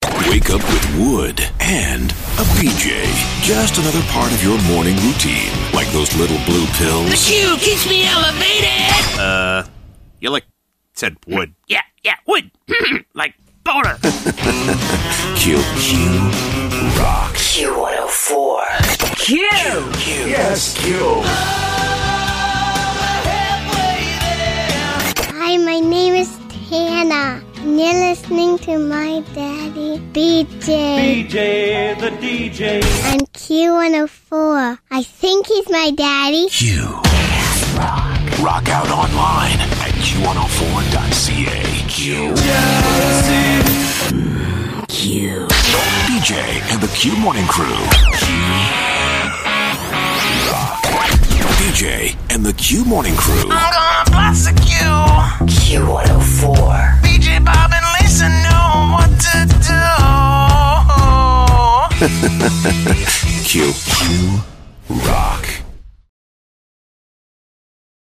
Q104 Morning Show Liners